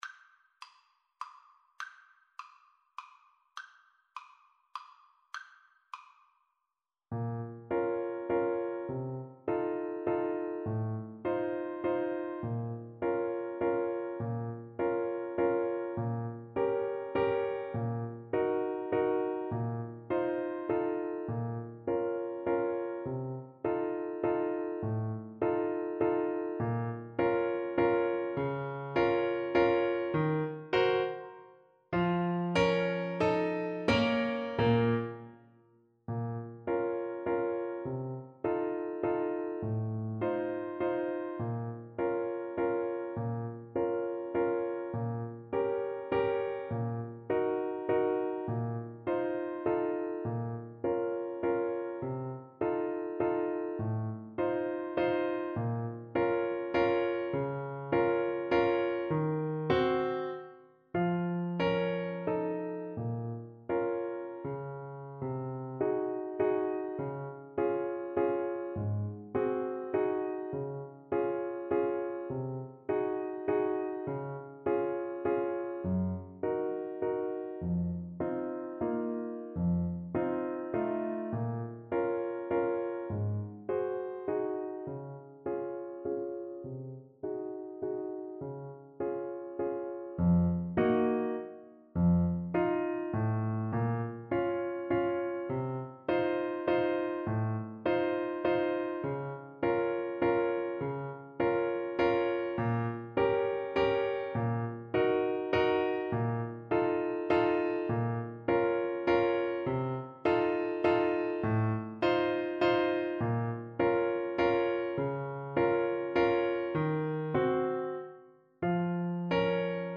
Classical Chopin, Frédéric Waltz Op. 69, No. 2 Alto Saxophone version
Play (or use space bar on your keyboard) Pause Music Playalong - Piano Accompaniment Playalong Band Accompaniment not yet available transpose reset tempo print settings full screen
Alto Saxophone
3/4 (View more 3/4 Music)
Moderato ( = 132)
Classical (View more Classical Saxophone Music)